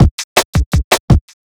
HP082BEAT1-R.wav